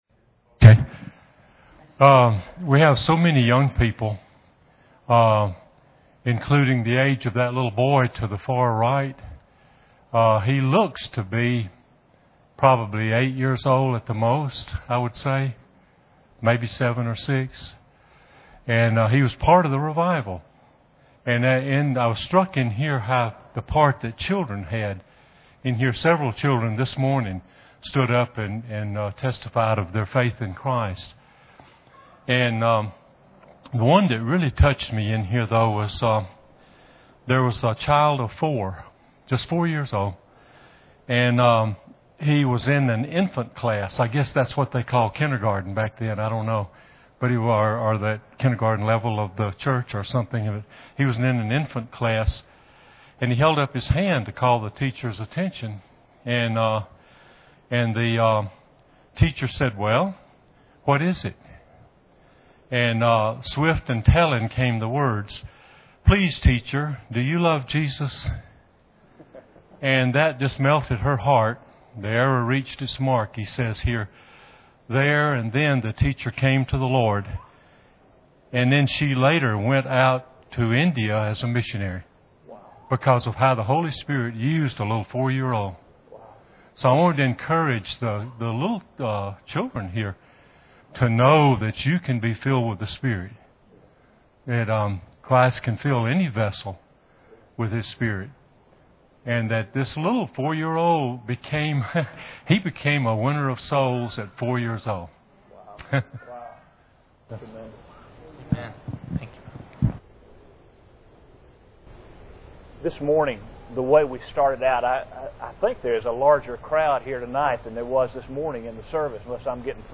Four Things to Know About Revival Service Type: Sunday Evening Preacher